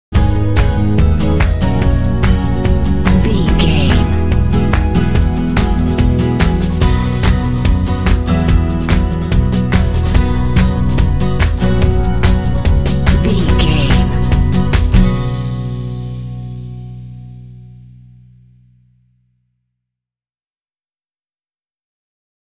Uplifting
Ionian/Major
Fast
happy
energetic
acoustic guitar
bass guitar
drums
alternative rock